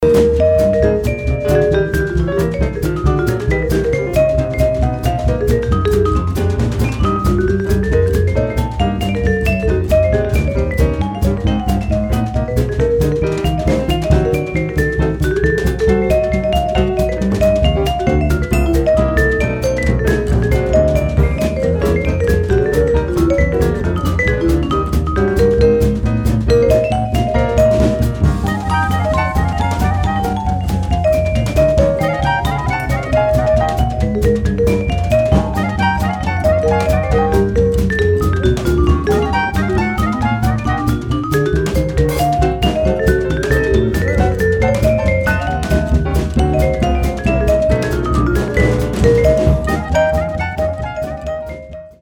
vibraphone
orgue Hammond
batterie.
saxo ténor
saxo baryton.